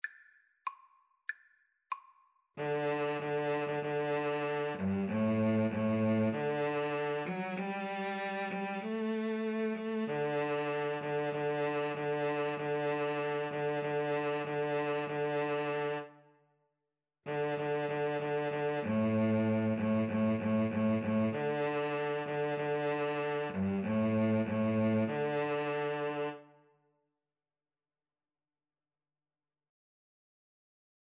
Free Sheet music for Violin-Cello Duet
D major (Sounding Pitch) (View more D major Music for Violin-Cello Duet )
2/4 (View more 2/4 Music)